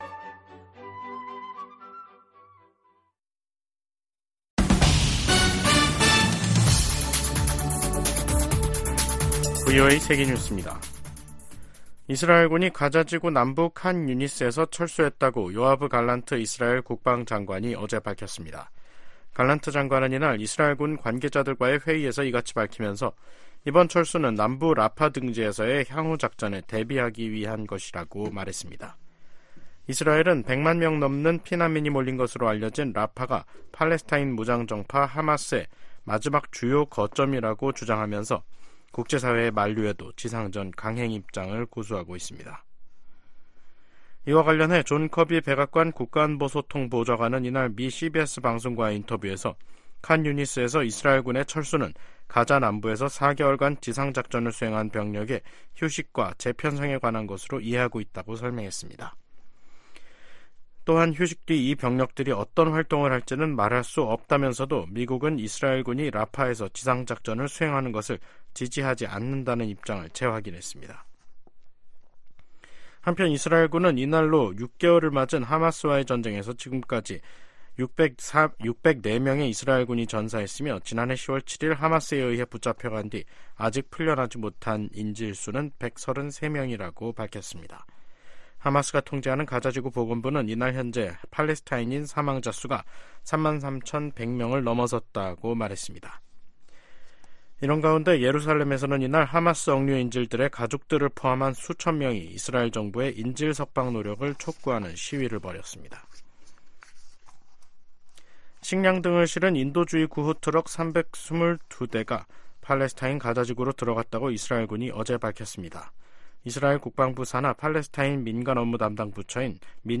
세계 뉴스와 함께 미국의 모든 것을 소개하는 '생방송 여기는 워싱턴입니다', 2024년 4월 8일 저녁 방송입니다. '지구촌 오늘'에서는 가자지구 휴전협상에 진전이 있다고 전해지는 가운데 이스라엘이 가자지구 남부지역에서 병력을 철수한 소식 전해드리고, '아메리카 나우'에서는 미국 대선 후보들의 선거자금 모금 경쟁이 치열해지고 있는 가운데 조 바이든 대통령이 3월 기준으로 현금 1억 9천200만 달러를 보유하고 있다고 밝힌 이야기 살펴보겠습니다.